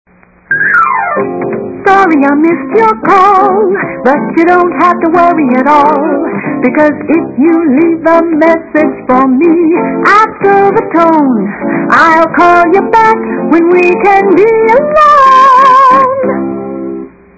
Up-tempo Contemporary